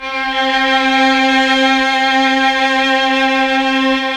Index of /90_sSampleCDs/Roland LCDP09 Keys of the 60s and 70s 1/KEY_Chamberlin/STR_Cham Slo Str